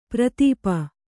♪ pratīpa